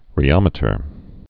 (rē-ŏmĭ-tər)